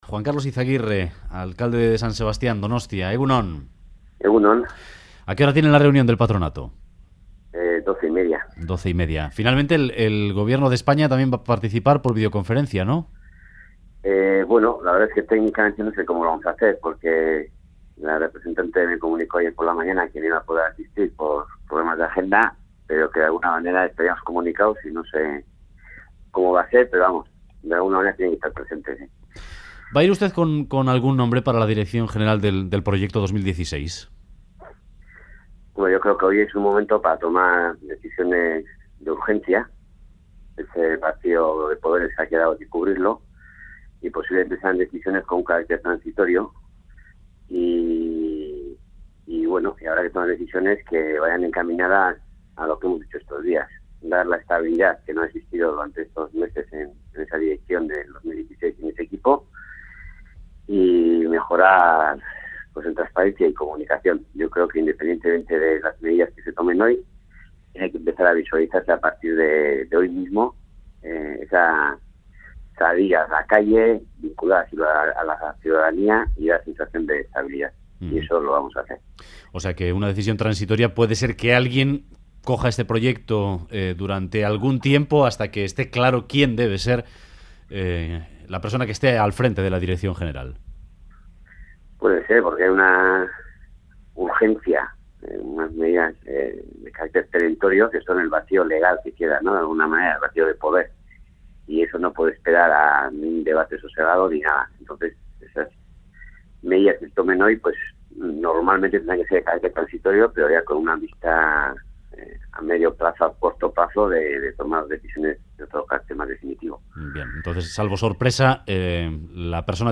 Donostia 2016 : En Radio Euskadi, el alcalde de San Sebastián, Juan Karlos Izagirre, ha dicho que hay que dotar de estabilidad a Donostia 2016 y comunicar mejor